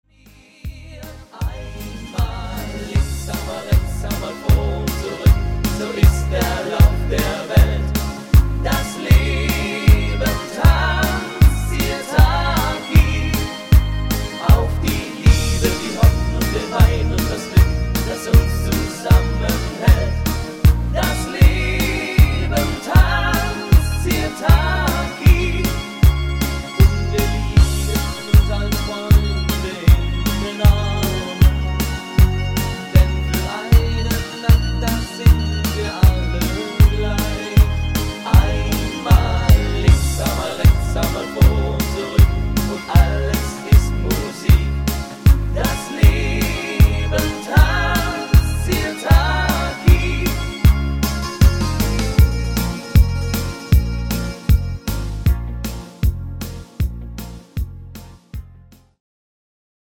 Rhythmus  Sirtaki
Art  Deutsch, Fasching und Stimmung, Party Hits